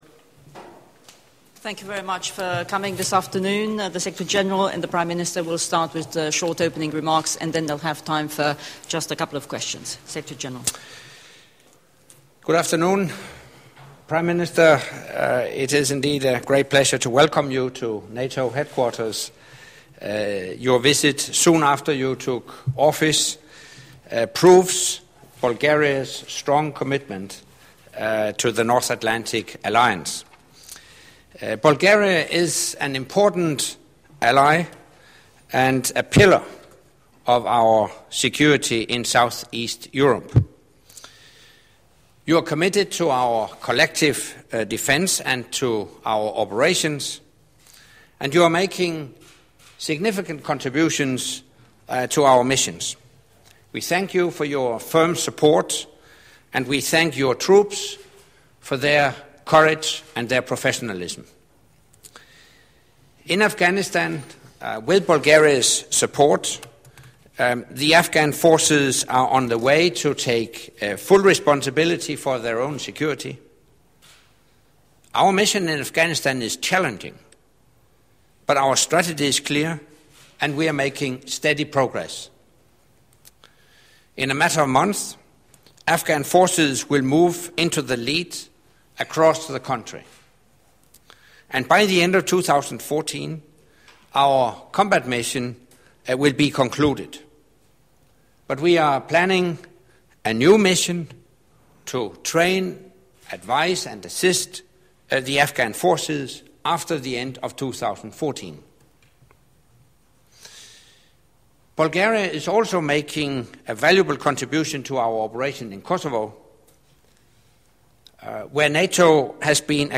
Joint press point with NATO Secretary General Anders Fogh Rasmussen and Marin Raykov, Prime Minister and Minister of Foreign Affairs of Bulgaria